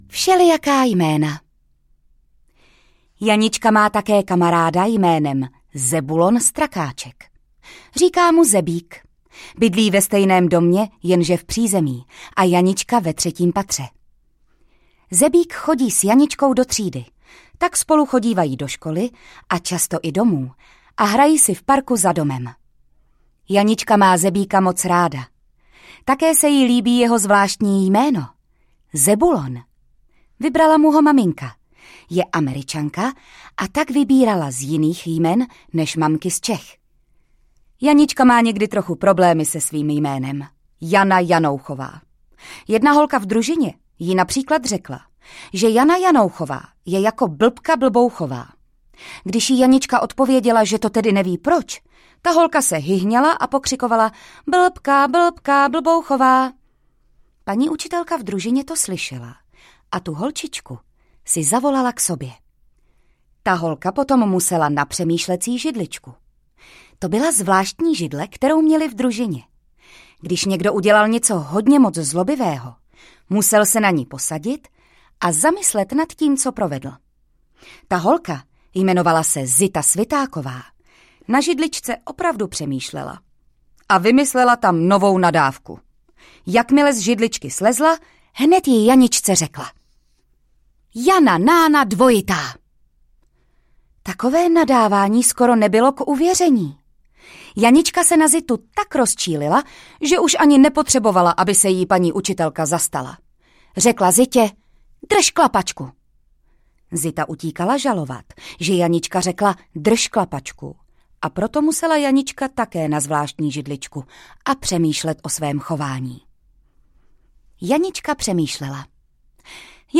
Audio knihaProč má Janička velrybu
Ukázka z knihy
• InterpretJitka Ježková